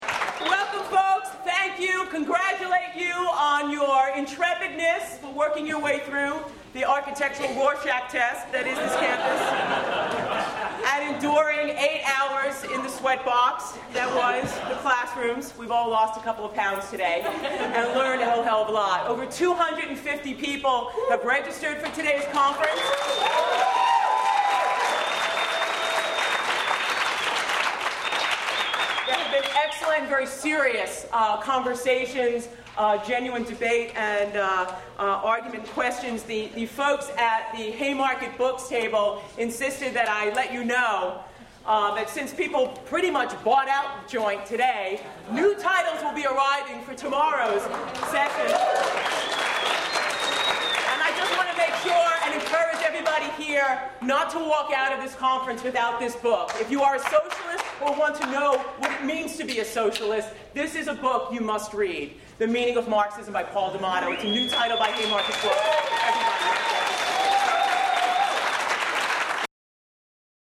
Midwest Socialism Conference
The Plenary session